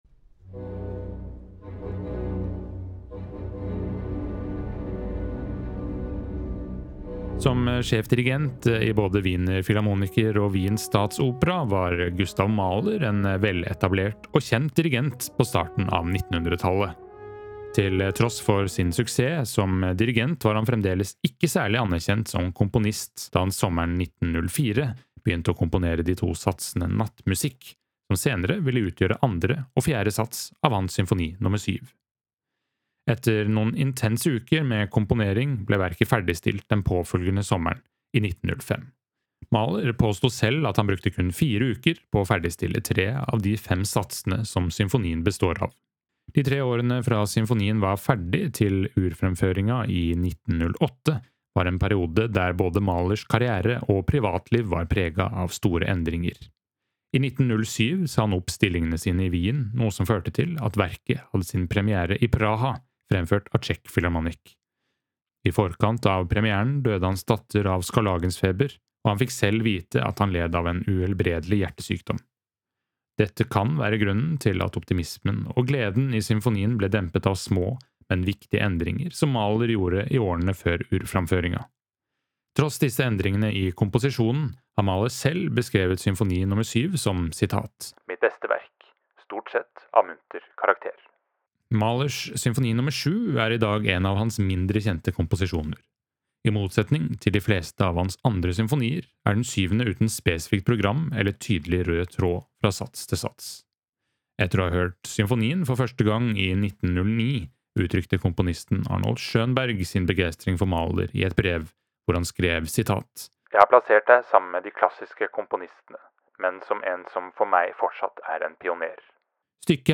VERKOMTALE-Mahlers-Symfoni-nr-7.mp3